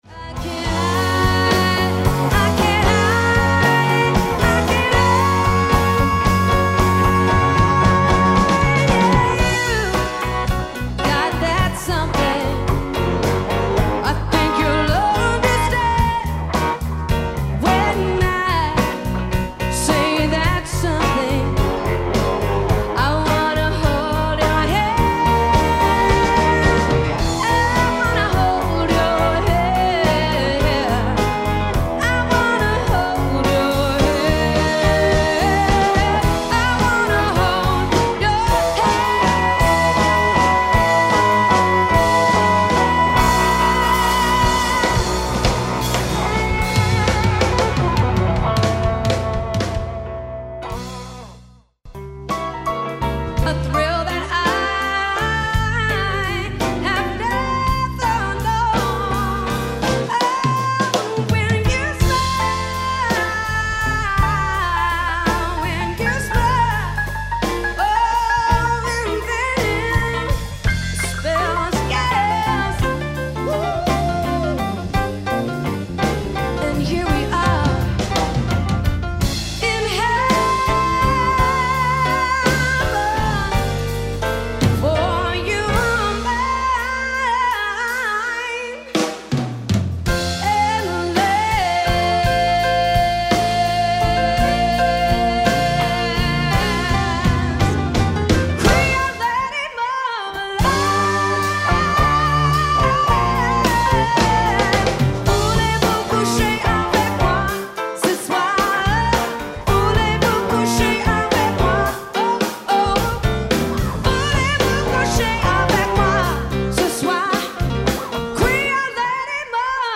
Dinner & Variety
Demo Quintet